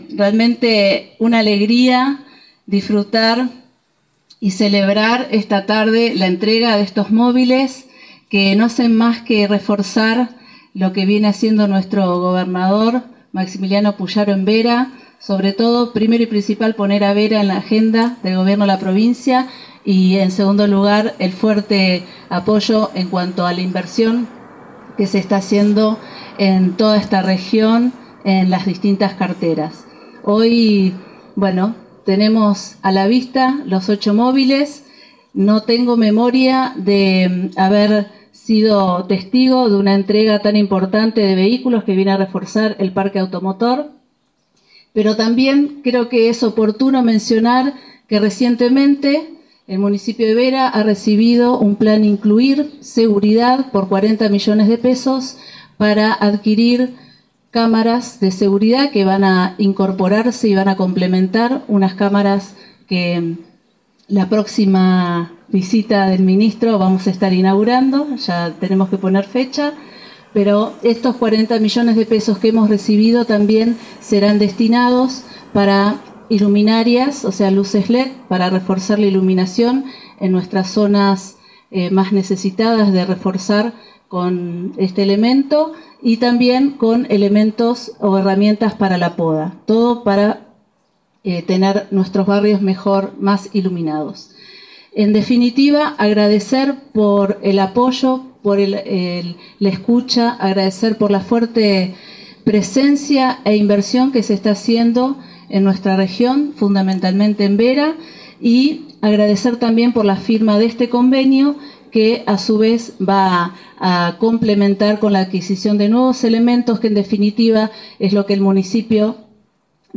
Fue en un acto realizado en la U.R XIX que fue encabezado por los ministros, Pablo Cococcioni y Fabián Bastia.
Paula Mitre – Intendente de la Ciudad de Vera